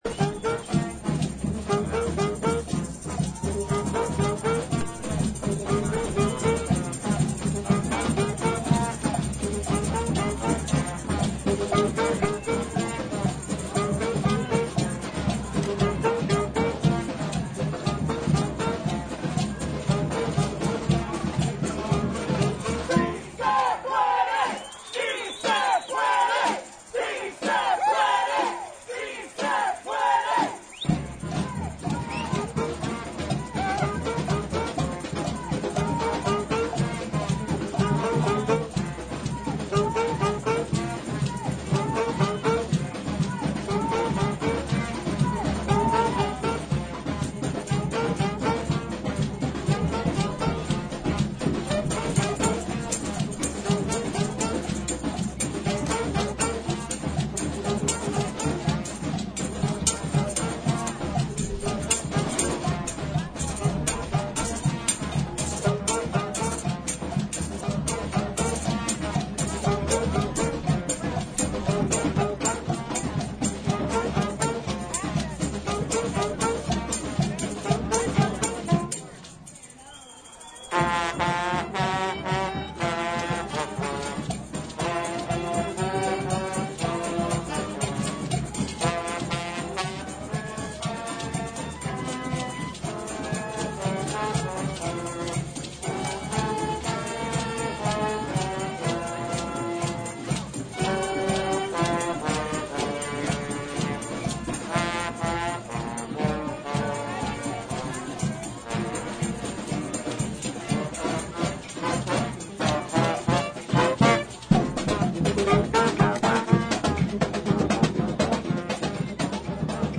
§Music from Pro-Choice March
Hear music from the Brass Liberation Orchestra, who joined the pro-choice march, playing along with the bullhorn chants.